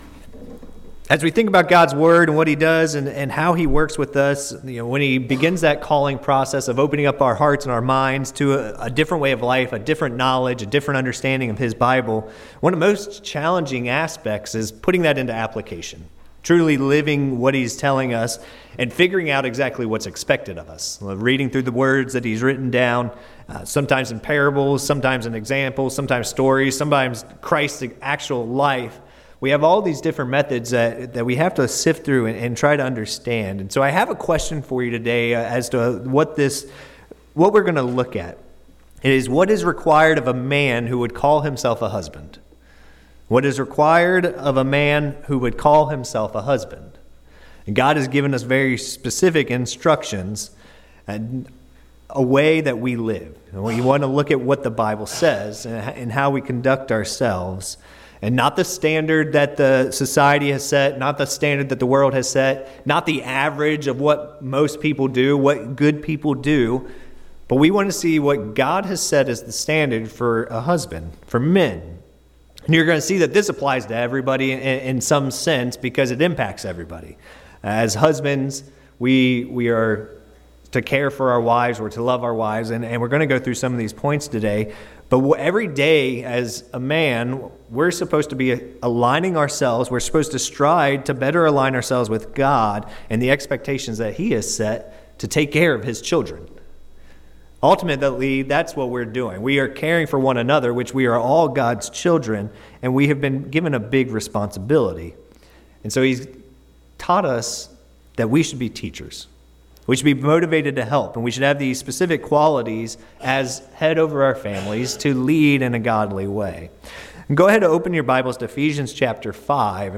This sermon covers five points to what is required of a man who calls himself a husband.